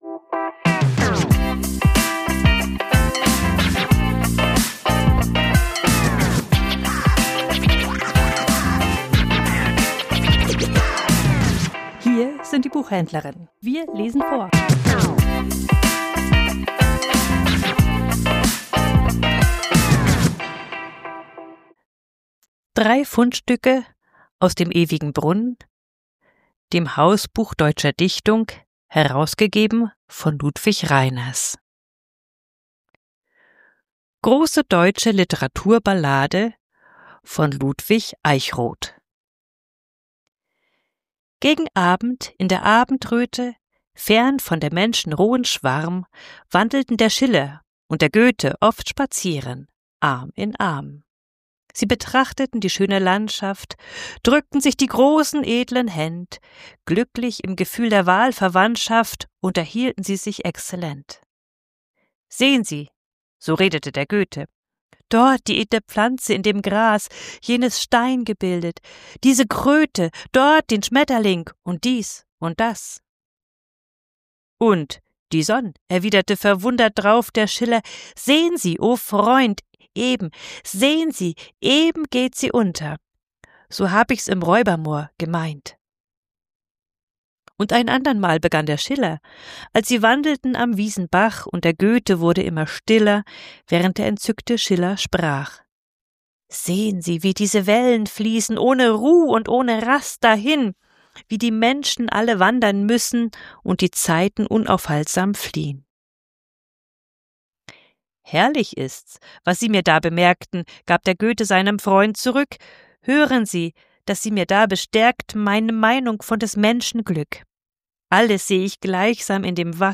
Vorgelesen: Der ewige Brunnen